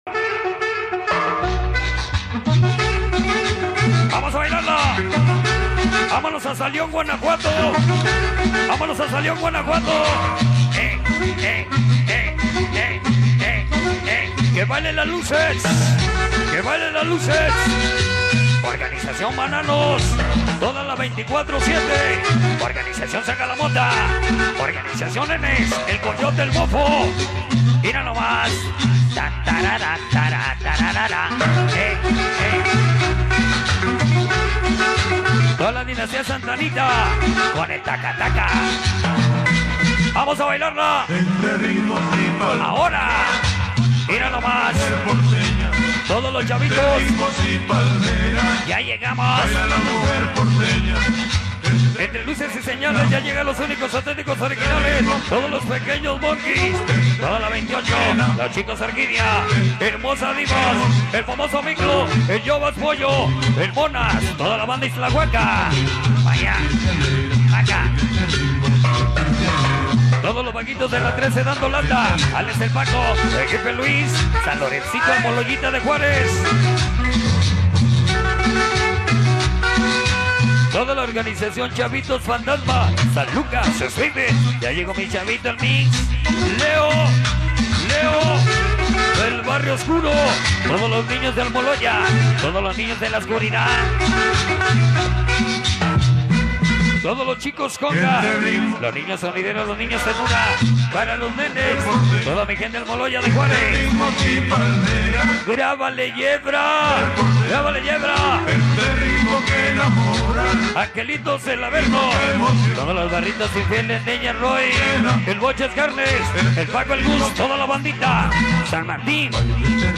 bailes sonideros